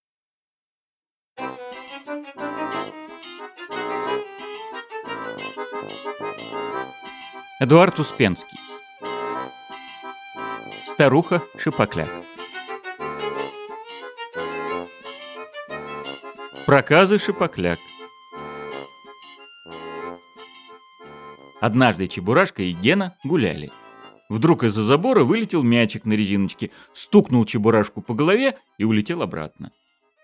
Аудиокнига Старуха Шапокляк | Библиотека аудиокниг
Aудиокнига Старуха Шапокляк Автор Эдуард Успенский Читает аудиокнигу Евгений Лебедев.